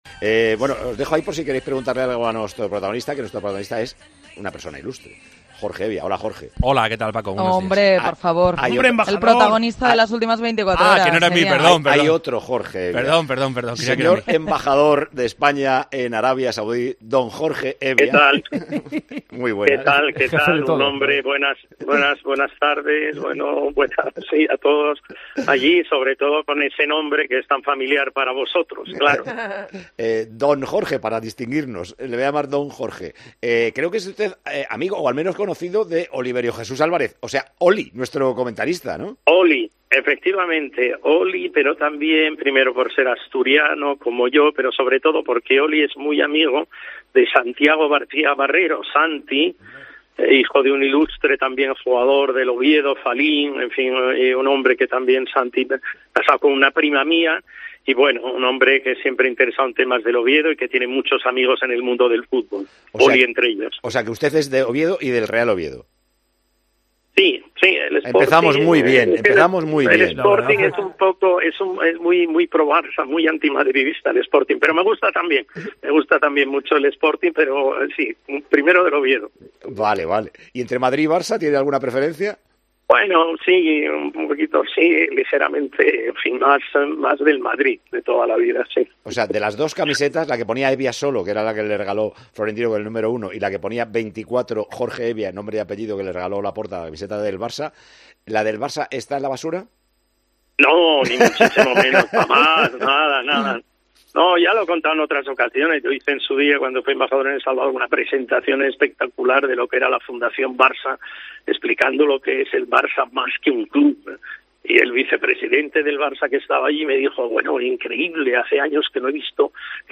Hemos entrevistado a Jorge Hevia, el embajador de España en Arabia Saudí.